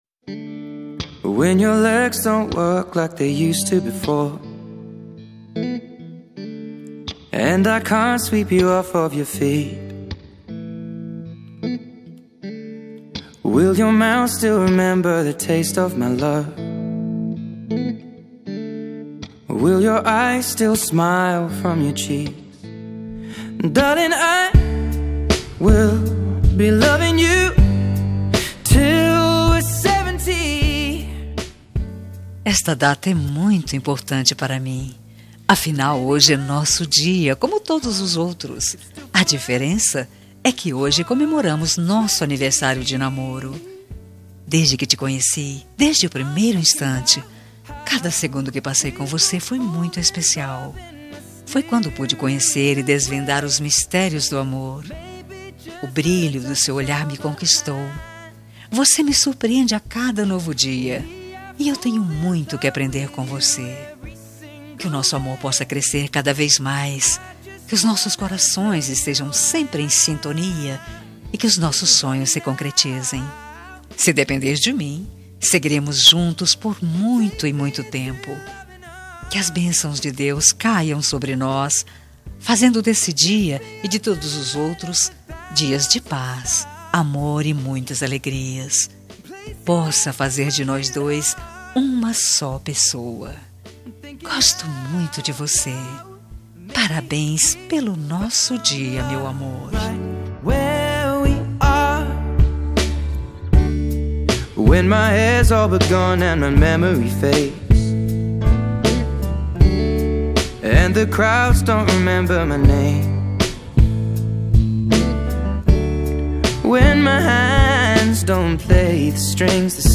Telemensagem Aniversário de Namoro – Voz Feminina – Cód: 8093 – Linda.
8093-aniv-namoro-fem.m4a